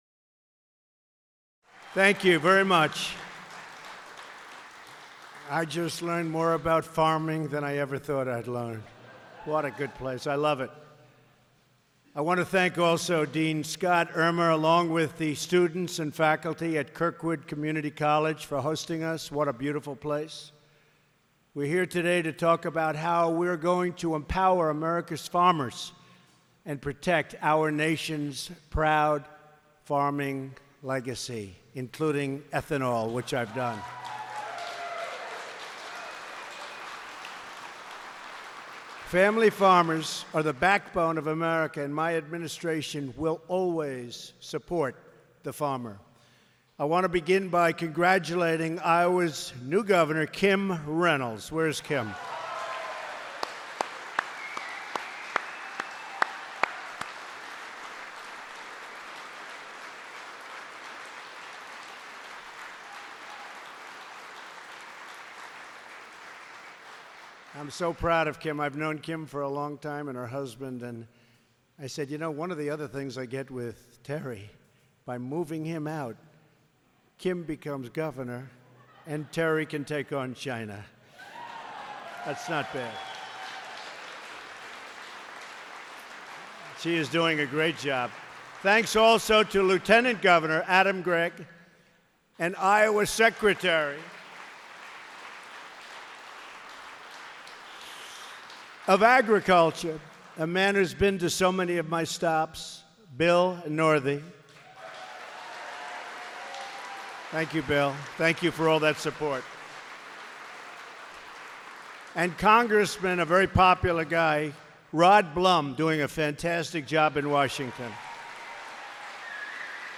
Remarks by President Trump on agricultural innovation